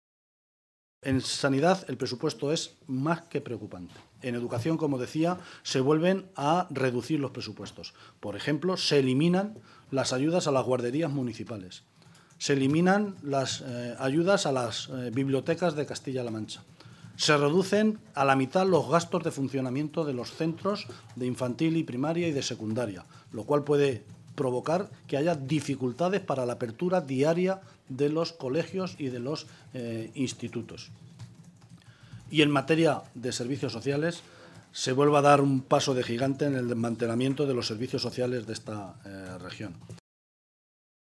Guijarro realizó estas declaraciones en una rueda de prensa conjunta con los responsables sindicales de CC OO y UGT